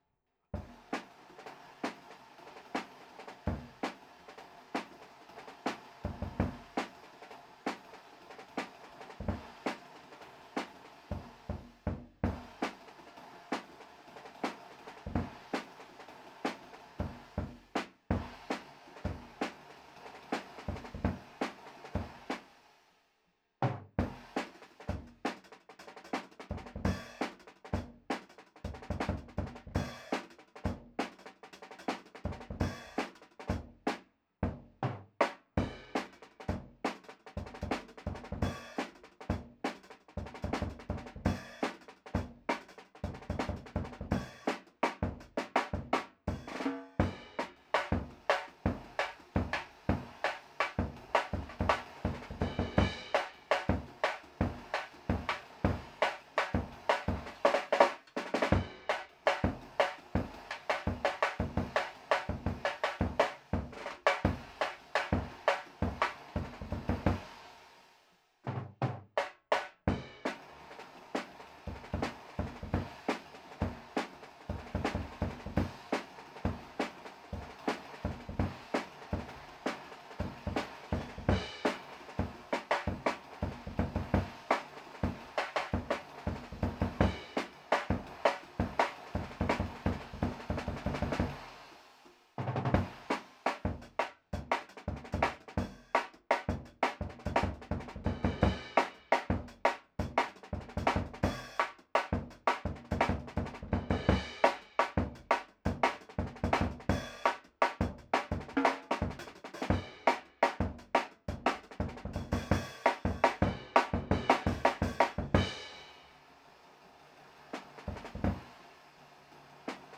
Index of /4 DRUM N BASS:JUNGLE BEATS/BEATS OF THE JUNGLE THAT ARE ANTIFUNGAL!!/RAW MULTITRACKS
TRASH ROOM_1.wav